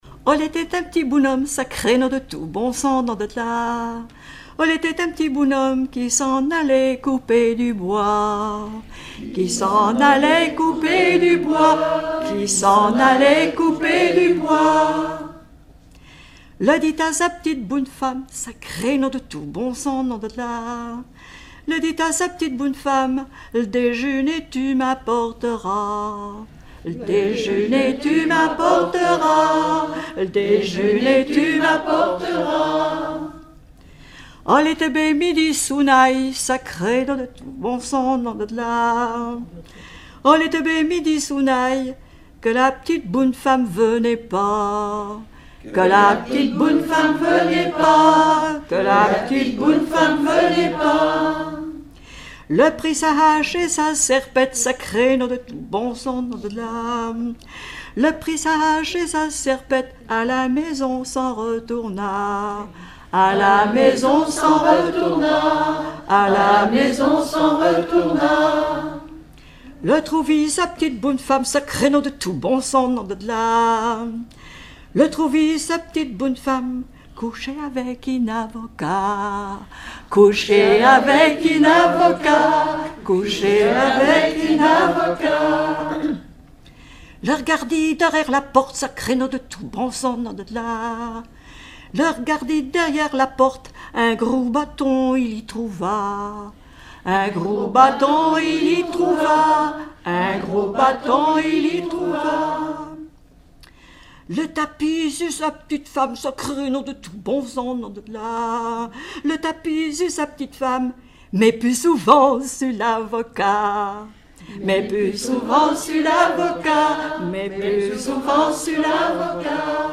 Genre laisse
Collectif-veillée (2ème prise de son)
Pièce musicale inédite